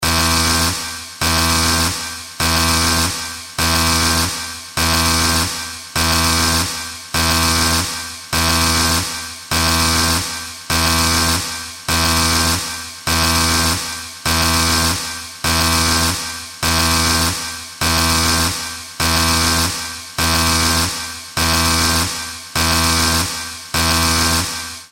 危機的な状況の警報アラーム音。
ブザー音の効果音。